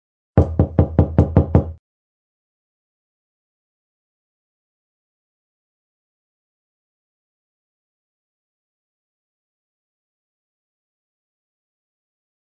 knock